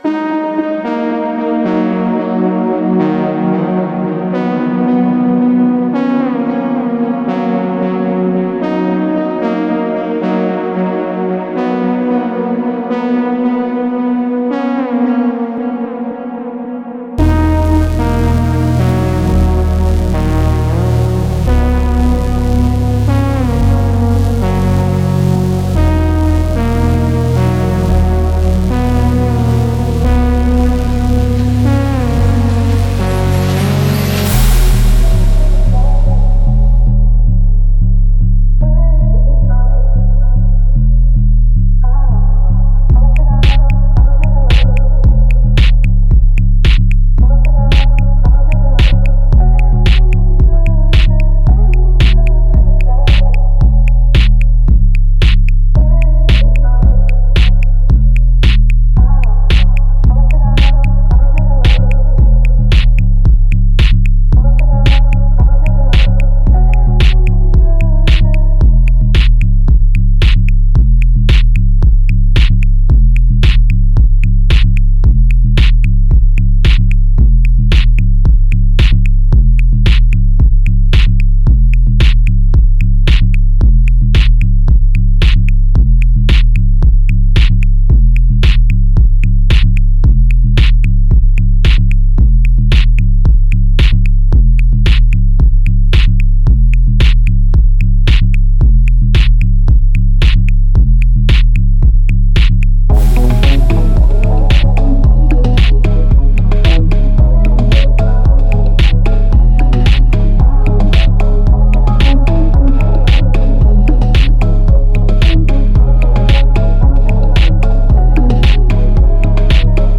Leaning into an EDM/House-oriented structure
The energy is restless.